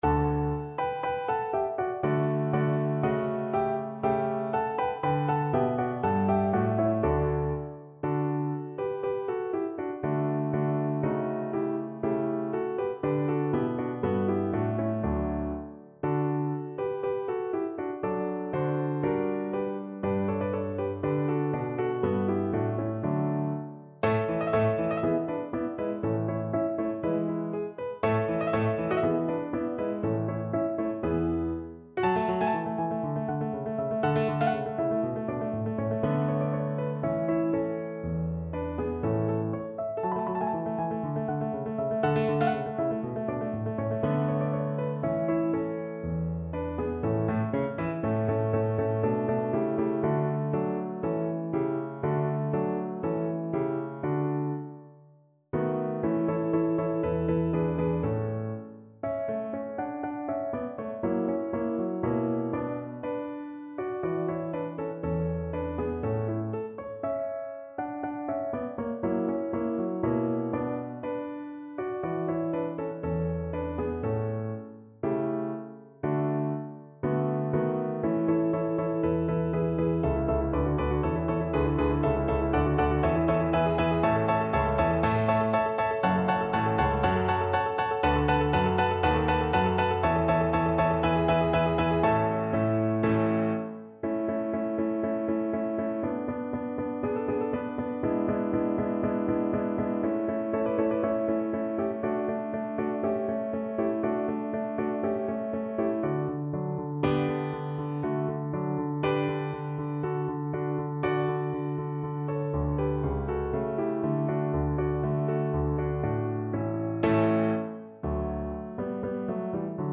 Alleluya Cello version
Cello
D major (Sounding Pitch) (View more D major Music for Cello )
2/4 (View more 2/4 Music)
Allegro non troppo (View more music marked Allegro)
D4-F#5
Classical (View more Classical Cello Music)